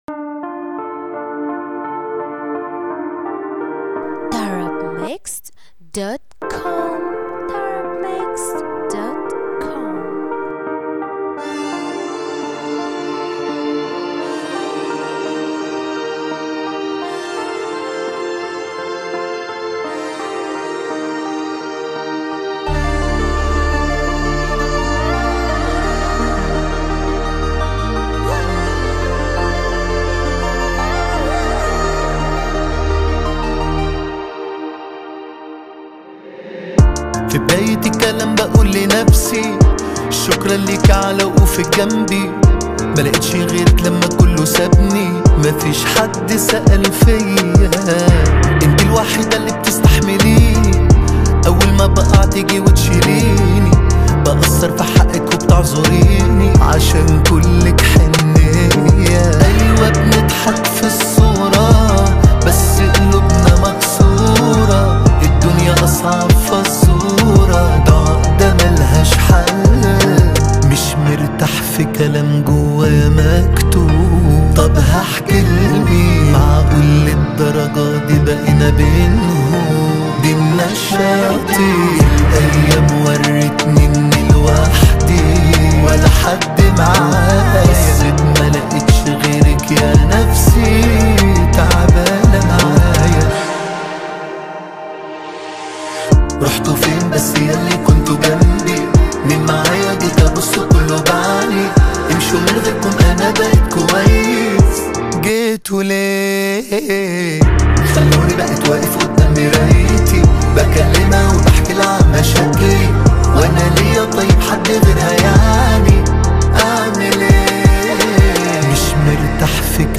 • النوع : arabic_song